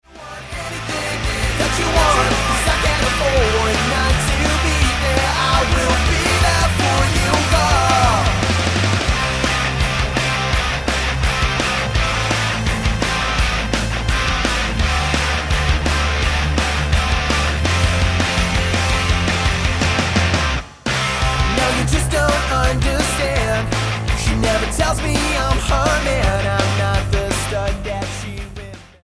STYLE: Rock
closes the EP with all the stops pulled out